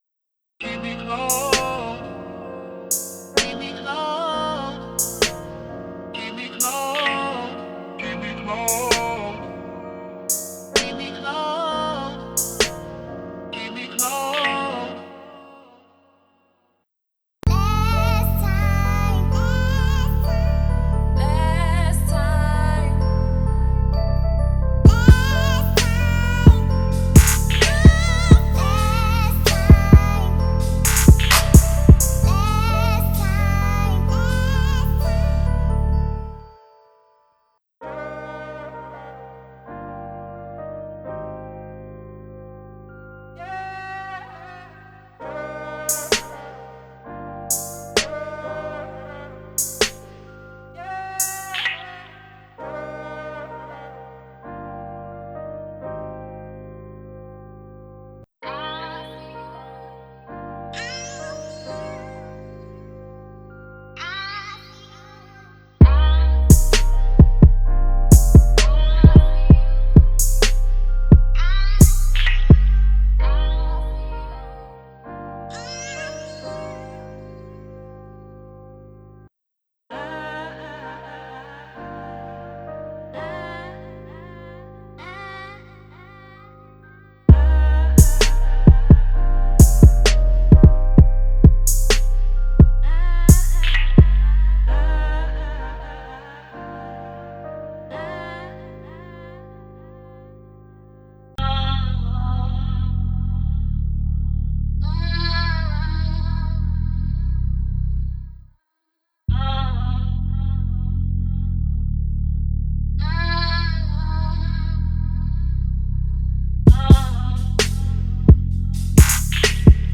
波-男声和女声
-钢鼓
放大器包络，滤波器包络，滤波器，自定义混响。
延迟，均衡器，相位器，失真，lfo和饱和度。